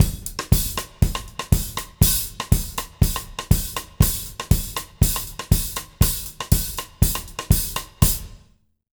120ZOUK 01-R.wav